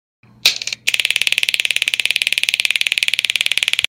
Taser Meme Effect Sound sound effects free download